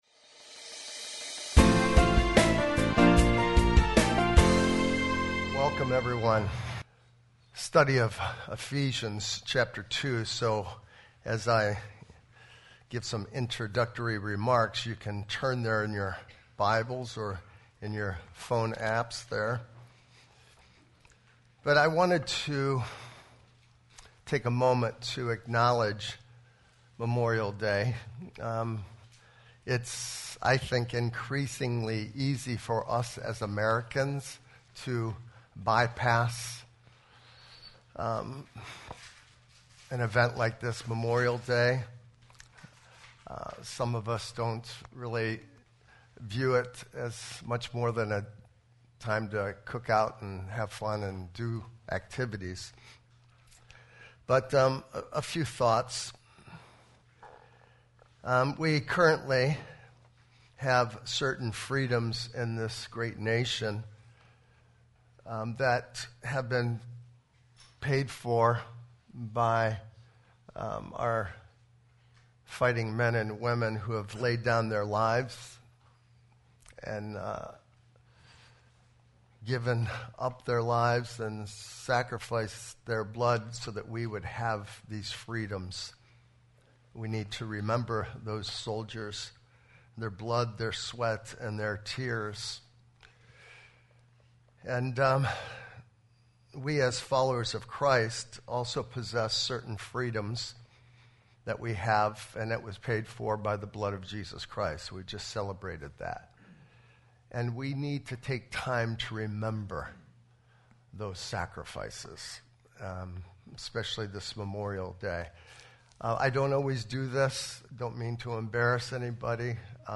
Sermon 5_28_23 Master.mp3